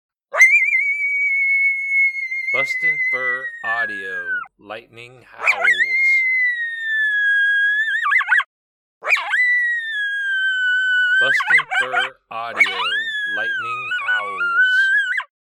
Young male Coyote howling at a very high pitch with good pitch breaks and yodeling.